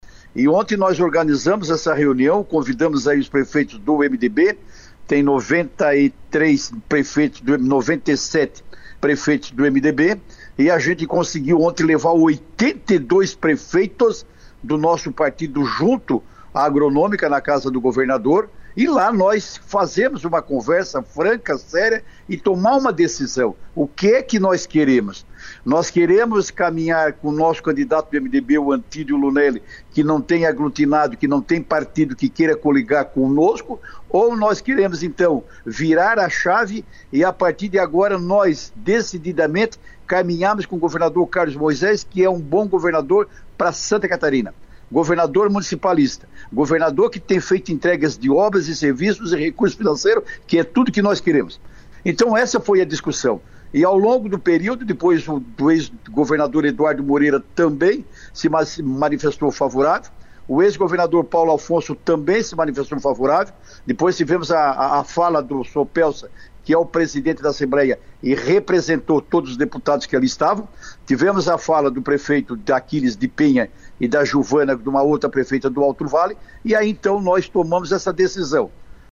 A declaração do prefeito foi dada hoje pela manhã reafirmando posicionamento adotado por 82 dos 97 prefeitos que o partido tem no Estado, além da bancada de deputados estaduais e os ex-governadores Eduardo Moreira e Paulo Afonso Vieira.